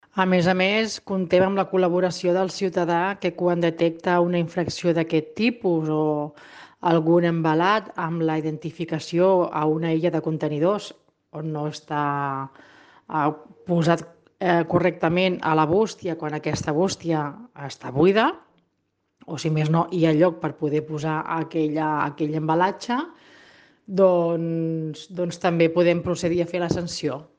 La regidora de civisme, Nàdia Cantero, en fa un balanç molt positiu.